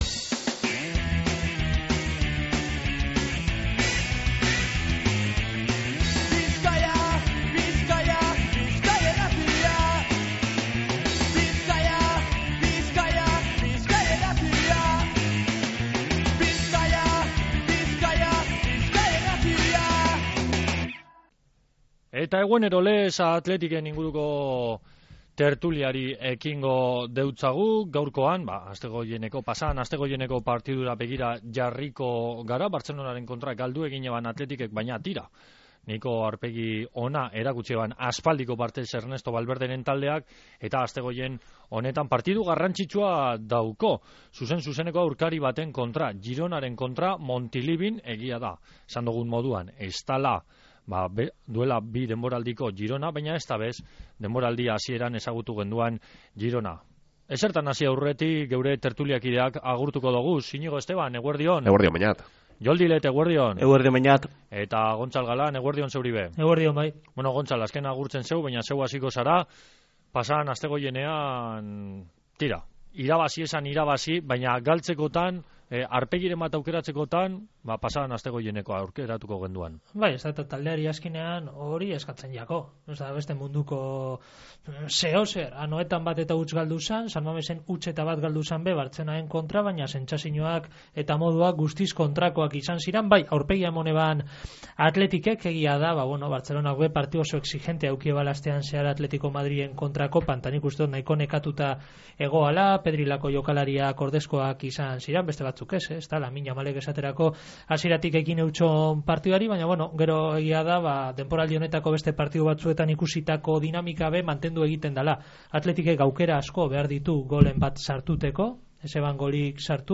Futbol tertulia | Bizkaia Irratia